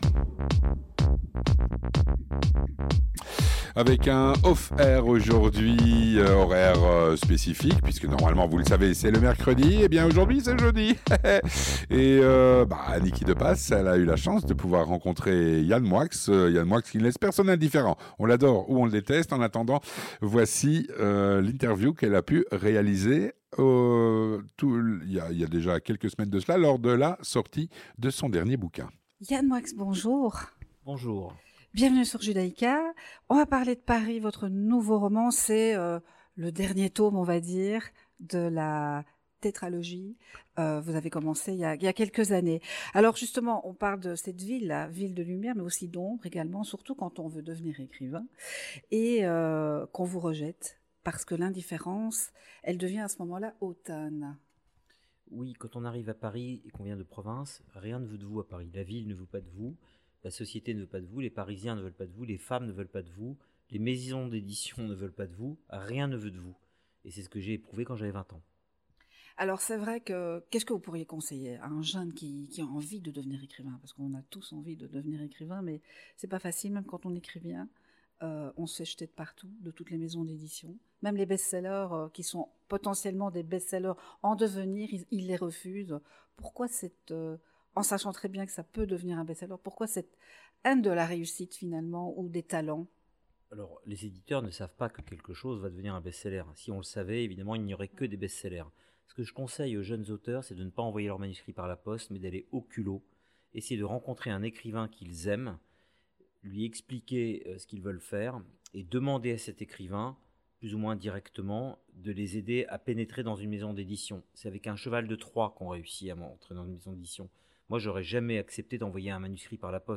Interview avec Yann Moix